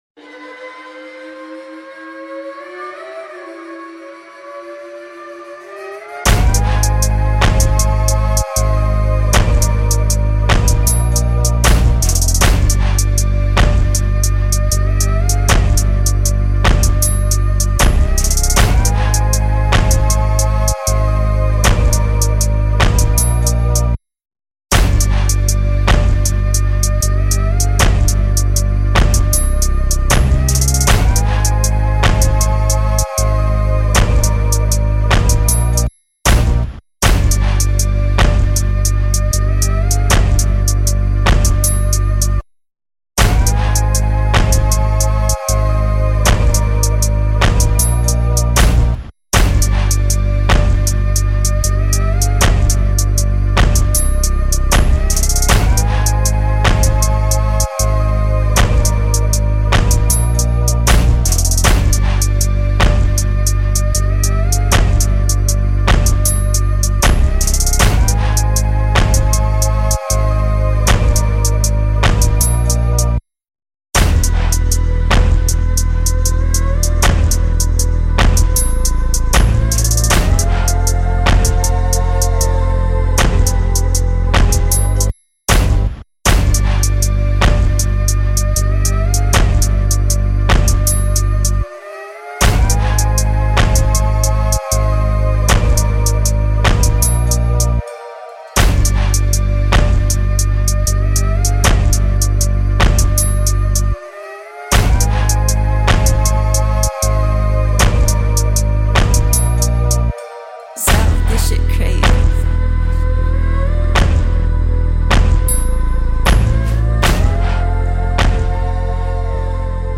This is the official instrumental
Sexy Drill Instrumentals